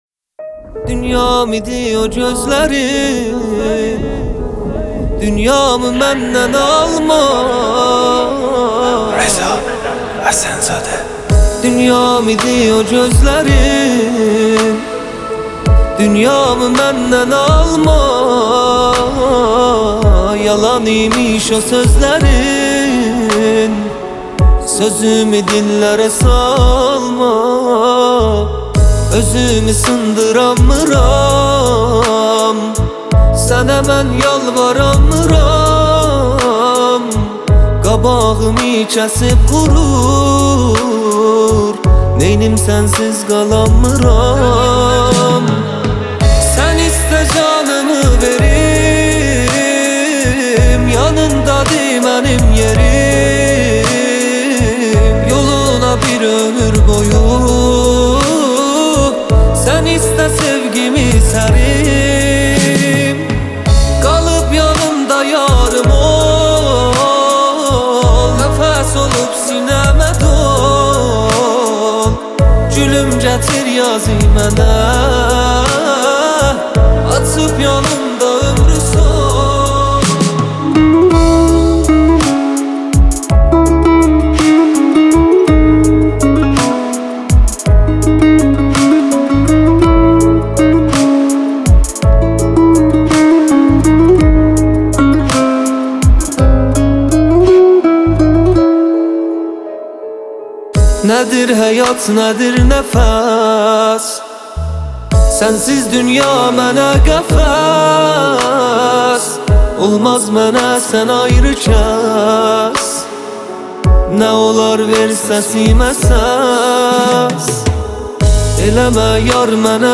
Turki